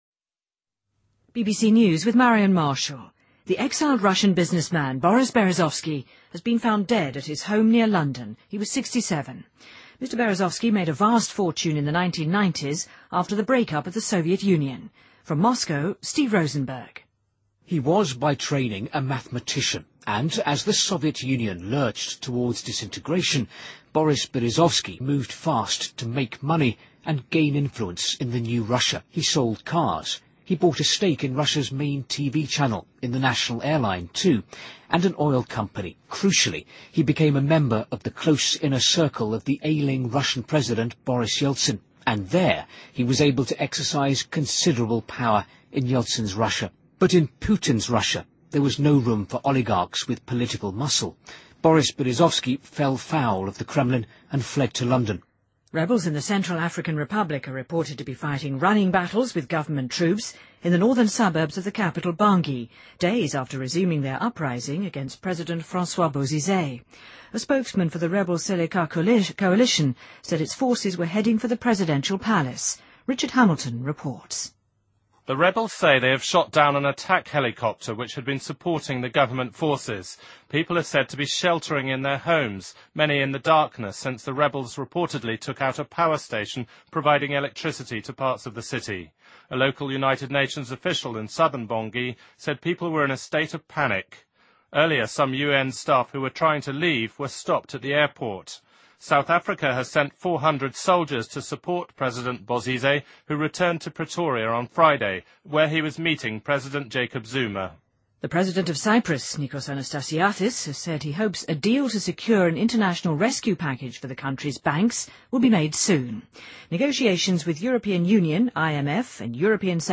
BBC news,2013-03-24